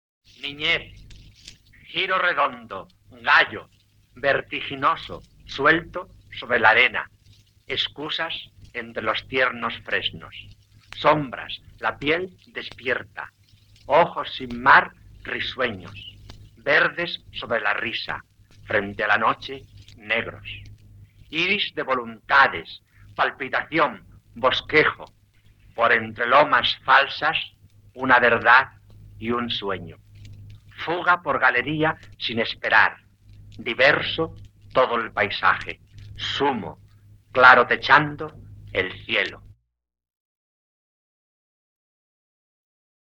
Autor del audio: el propio autor